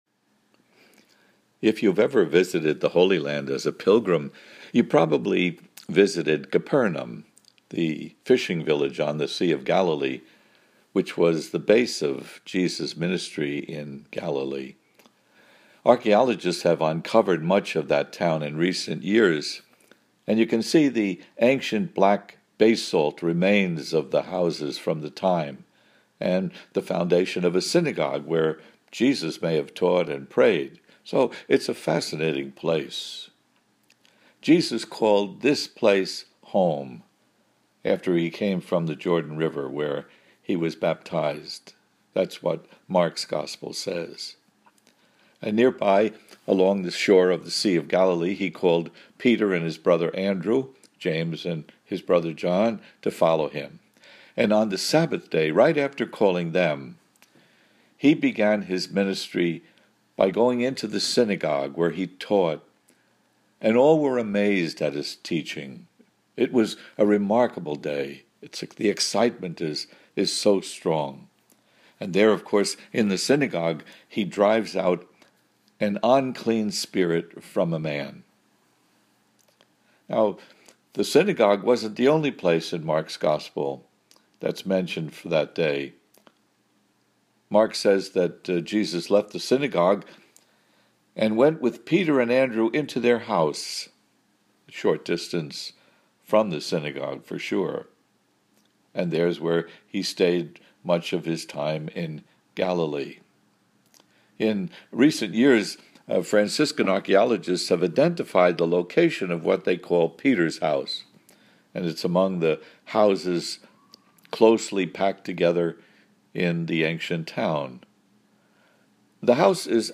Audio version of homily here: